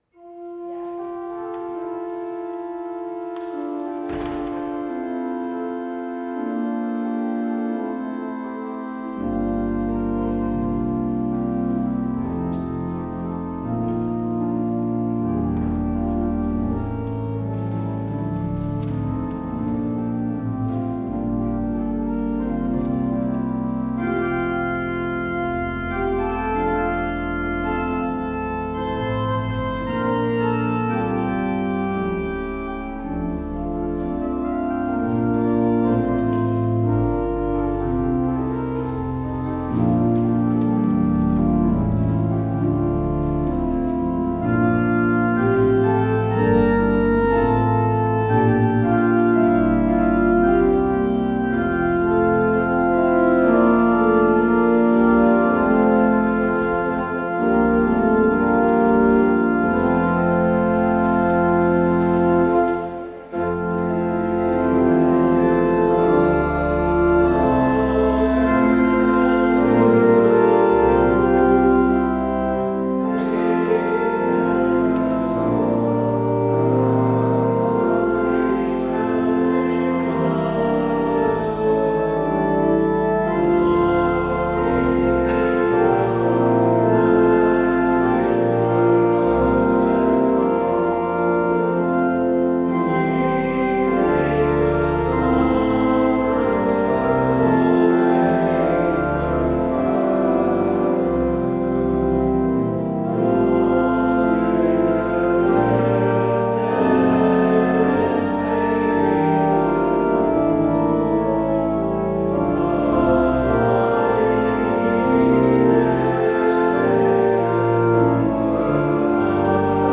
Het slotlied is: NLB 912.